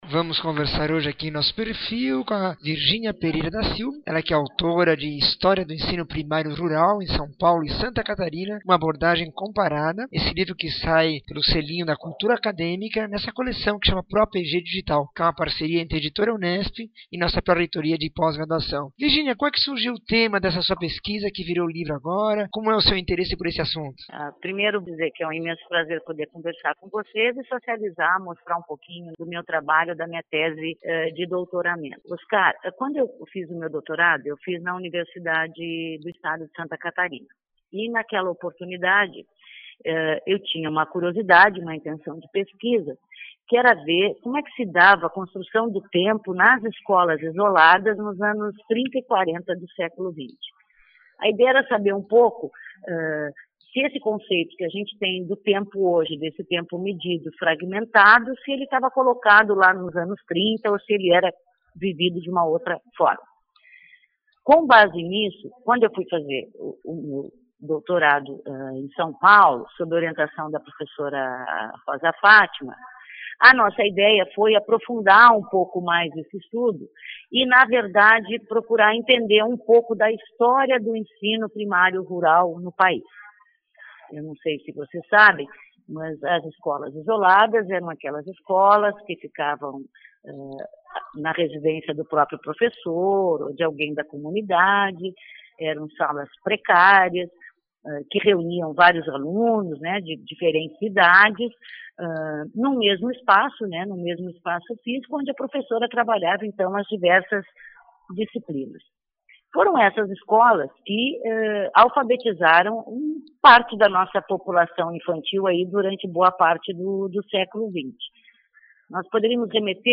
entrevista 2064